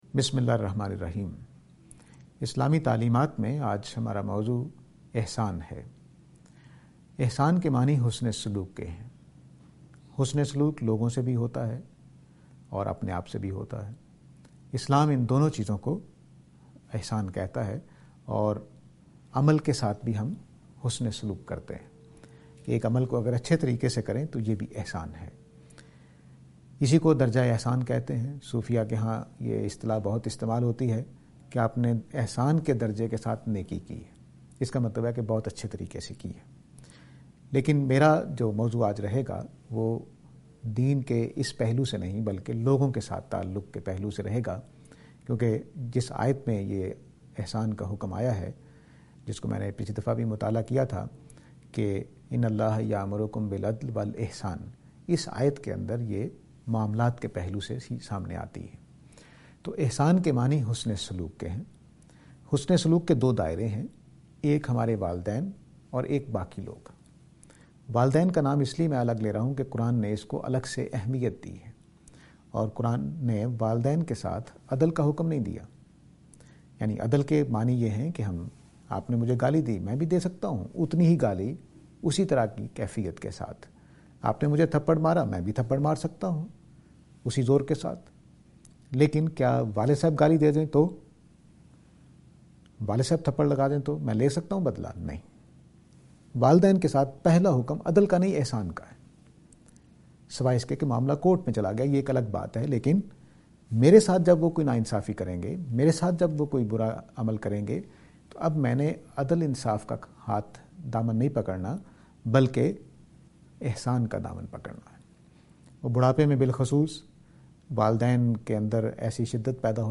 This lecture is and attempt to answer the question "Beneficence".